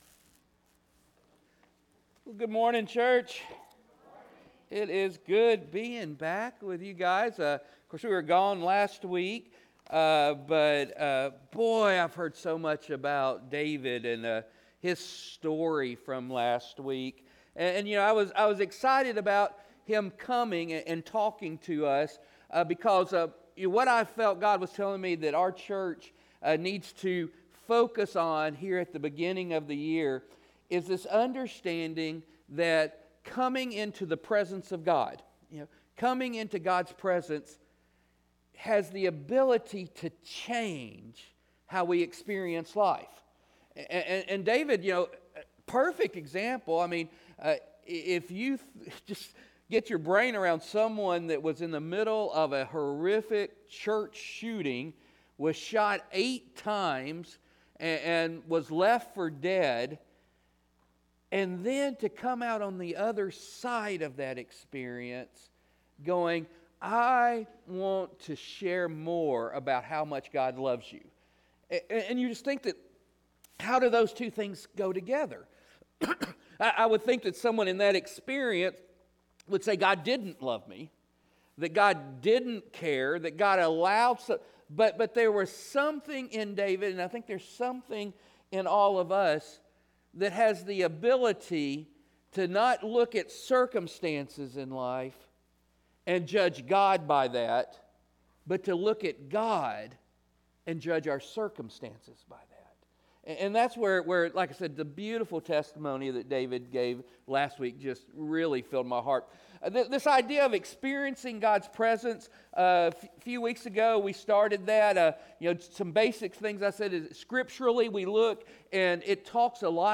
The Presence of God Service Type: Worship Service Experiencing God’s Presence 3 God is spirit.